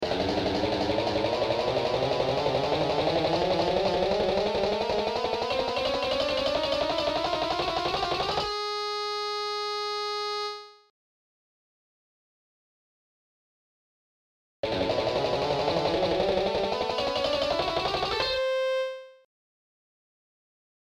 Sweeping+chromatic+scale.mp3